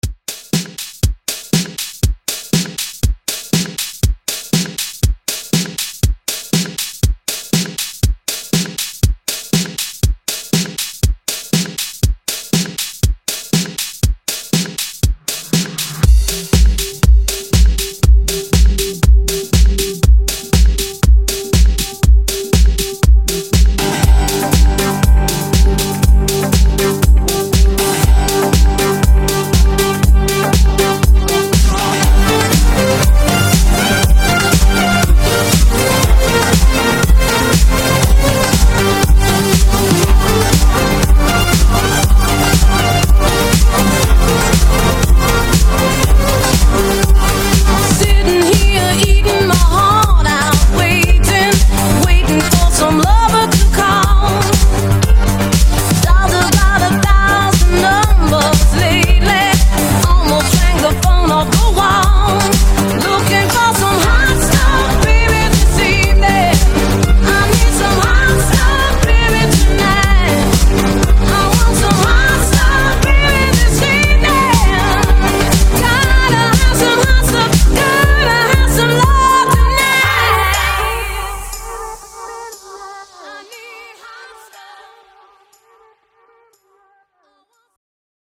Genres: RE-DRUM , ROCK , TOP40
Clean BPM: 113 Time